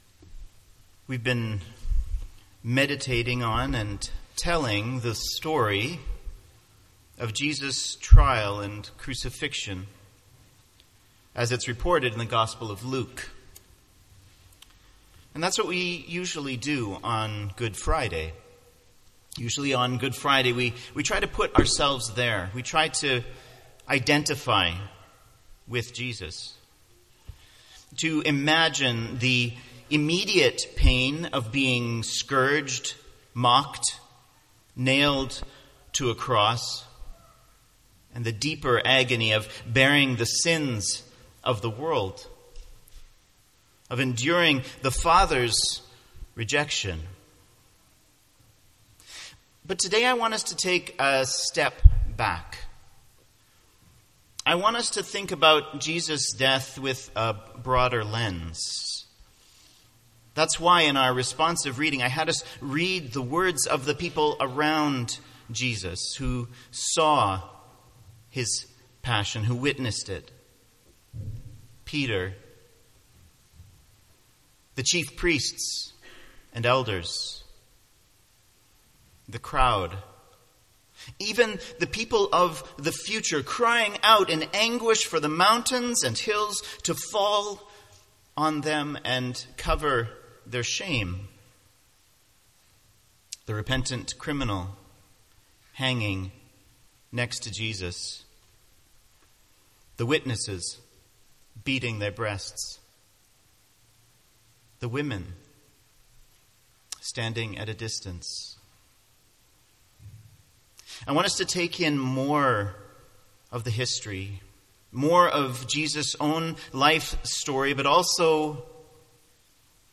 Good Friday Message